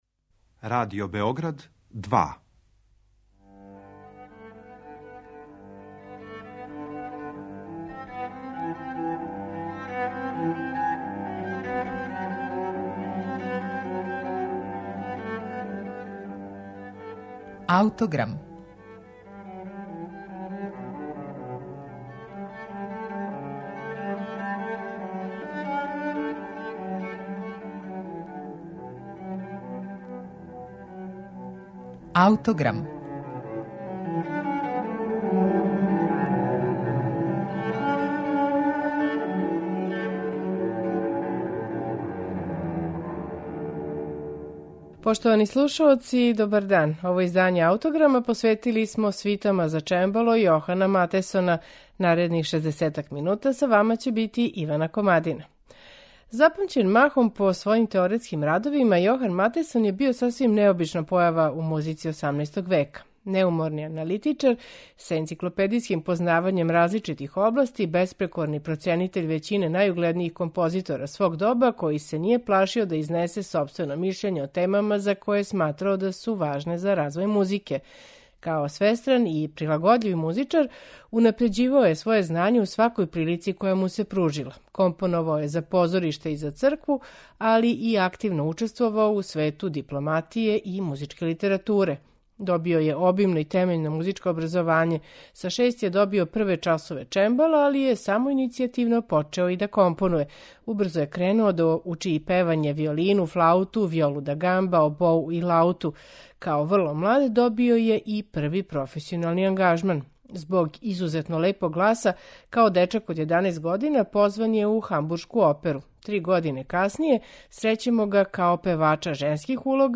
'Аутограм' смо данас посветили свитама за чембало Јохана Матесона.
Писани су у форми француске свите